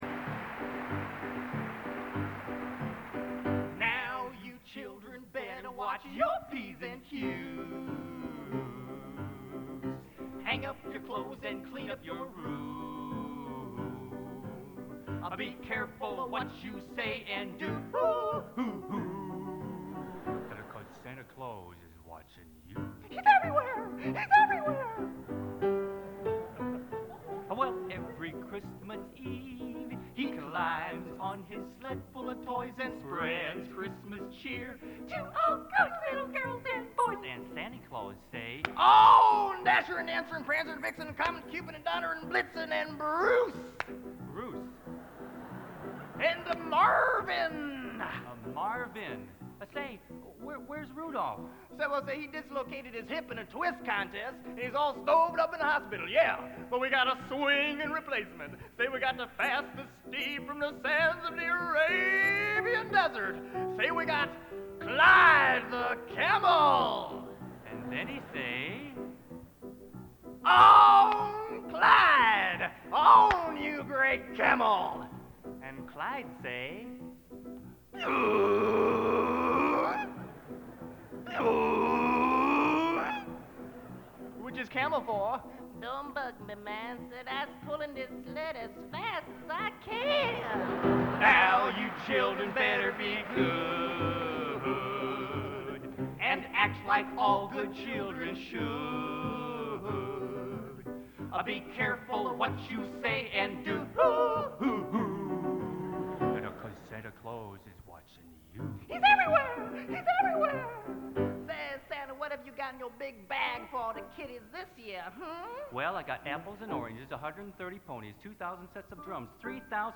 Genre: | Type: Christmas Show |